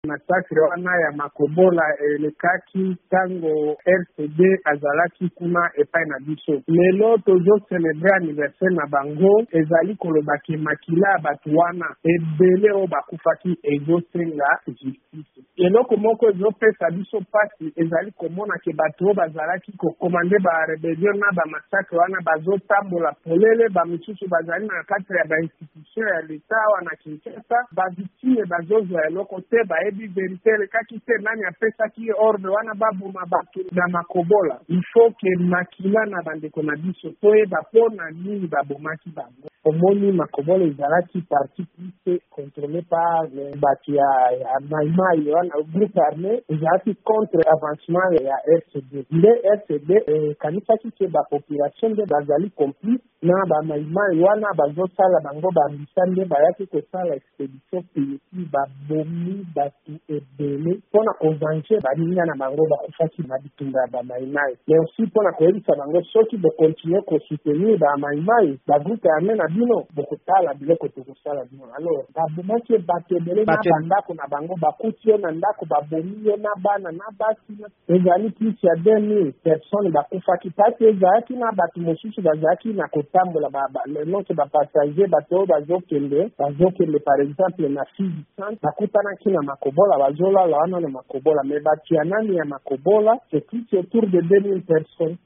VOA Lingala epesaki maloba na moko ya banzeneneke